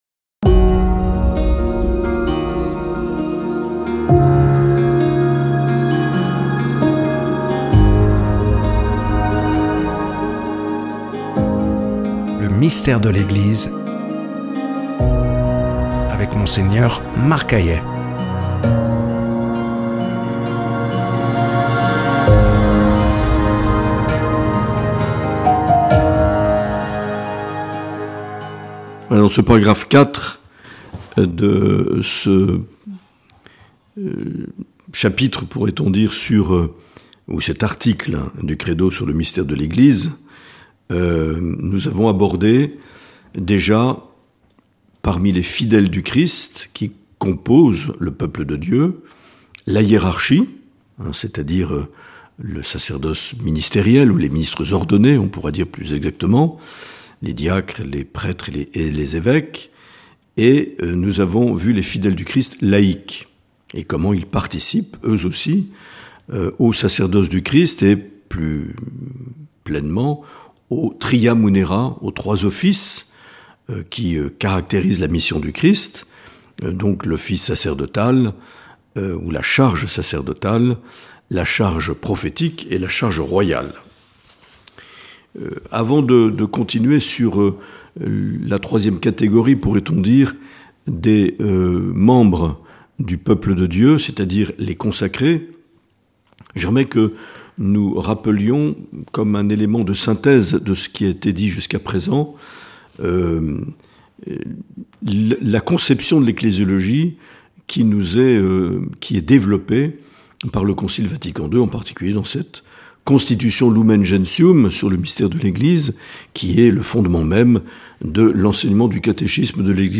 Une émission présentée par
Présentateur(trice)